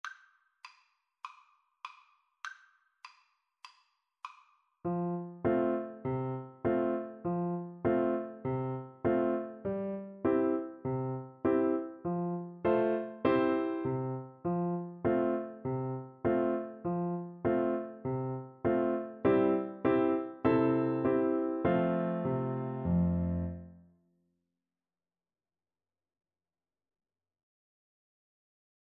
Play (or use space bar on your keyboard) Pause Music Playalong - Piano Accompaniment Playalong Band Accompaniment not yet available transpose reset tempo print settings full screen
Allegretto
C major (Sounding Pitch) (View more C major Music for Recorder )
4/4 (View more 4/4 Music)
mary_had_a_little_lamb_REC_kar1.mp3